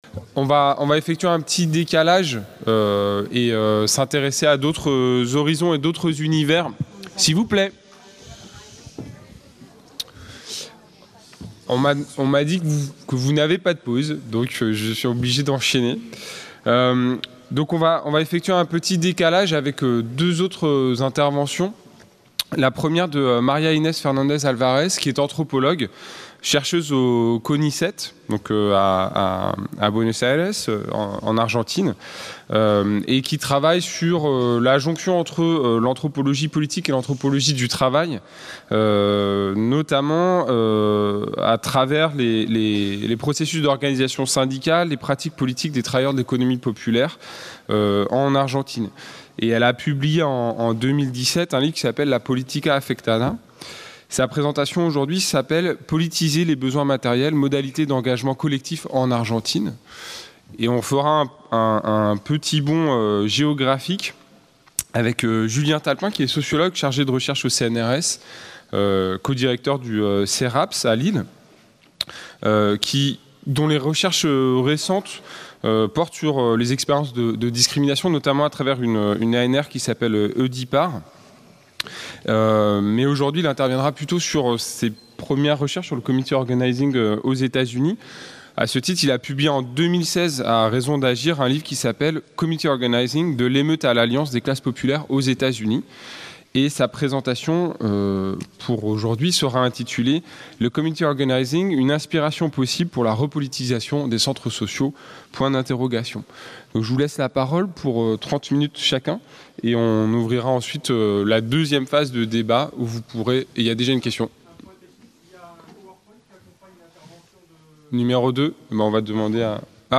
Table ronde 3